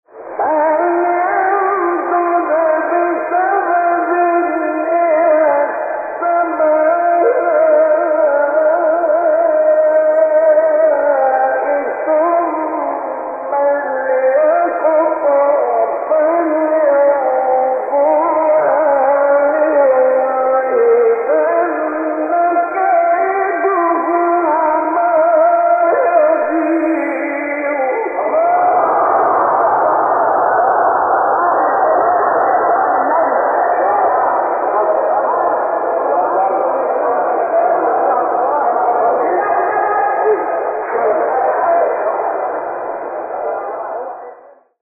آیه 15 سوره حج استاد مصطفی اسماعیل | نغمات قرآن | دانلود تلاوت قرآن